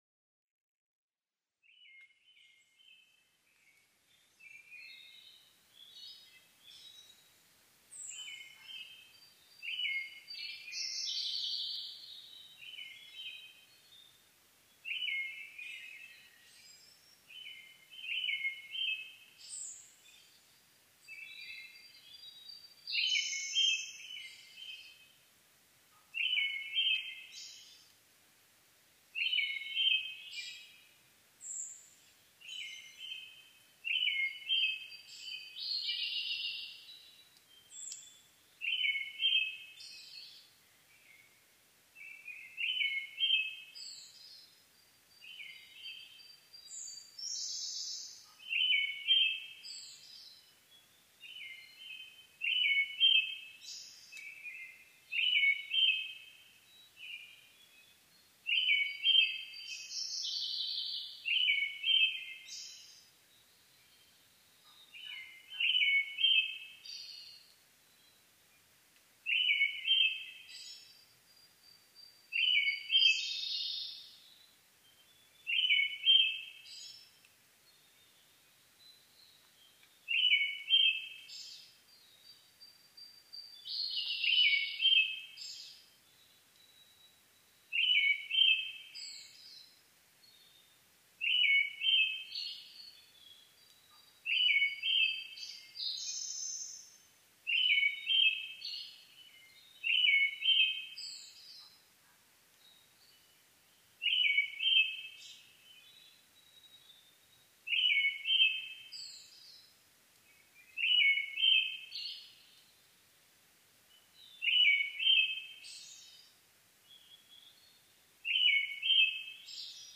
アカハラ　Turdus chrysolausツグミ科
日光市土呂部　alt=1210m
Mic: built-in Mic.
薄暗くなりつつある時間帯、森のあちこちからアカハラの声が響いてきます。
他の自然音：コルリ、タゴガエル、キビタキ、ウグイス